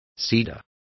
Complete with pronunciation of the translation of cedar.